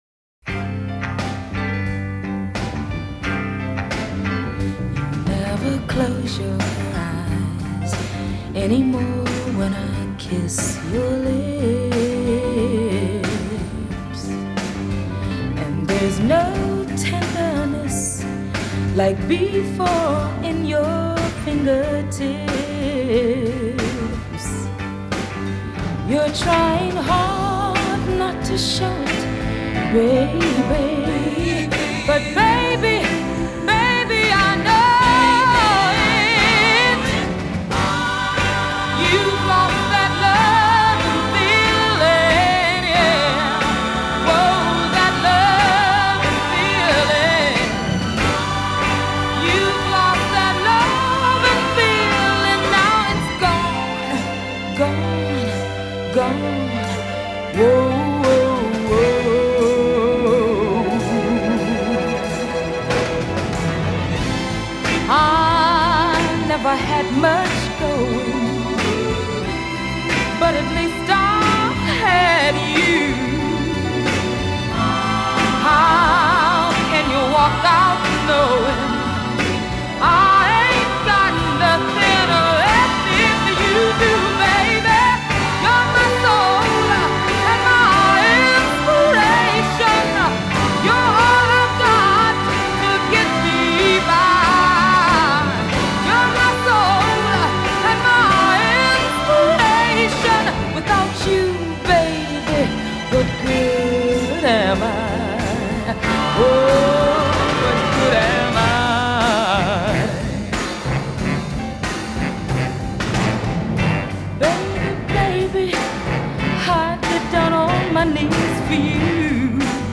Genre: Funk / Soul